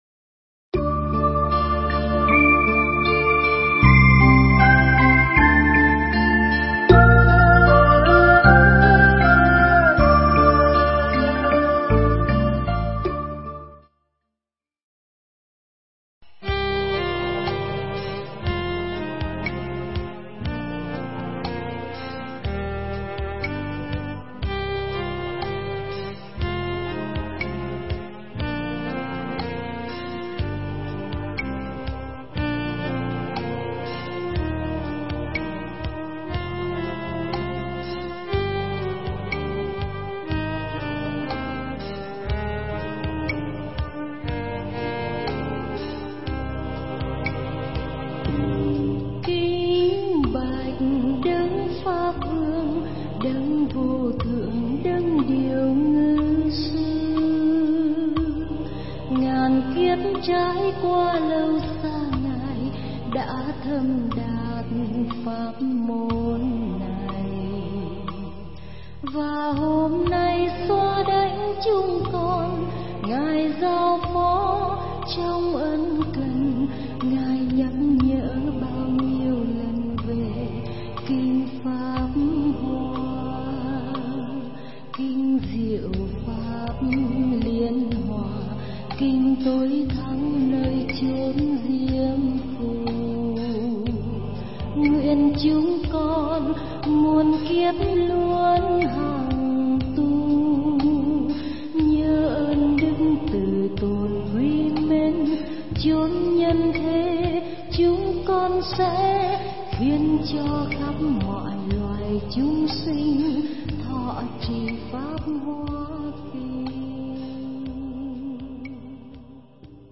Mp3 Thuyết Giảng Kế Thừa Tổ Nghiệp – Hòa Thượng Thích Trí Quảng giảng tại Tổ Đình Giác Lâm, ngày 7 tháng 6 năm 2014, (ngày 10 tháng 5 năm Giáp Ngọ)